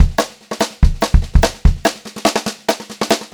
144SPBEAT4-L.wav